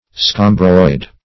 scomberoid - definition of scomberoid - synonyms, pronunciation, spelling from Free Dictionary
Search Result for " scomberoid" : The Collaborative International Dictionary of English v.0.48: Scomberoid \Scom"ber*oid\, a. & n. [Cf. F. scomb['e]ro["i]de.]